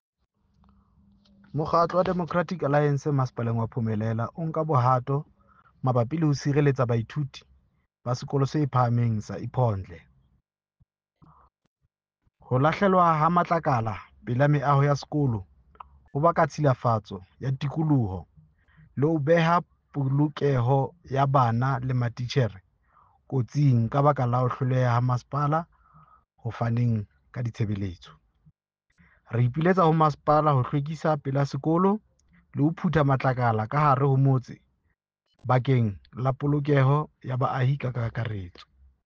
Sesotho soundbite by Cllr Diphapang Mofokeng.